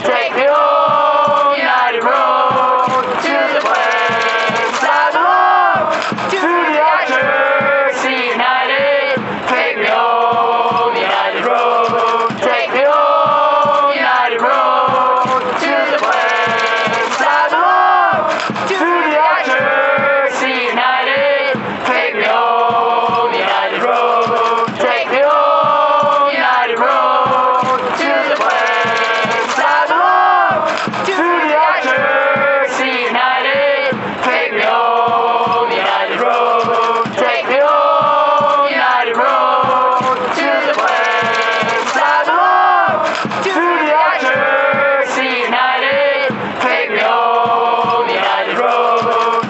Classic Football Songs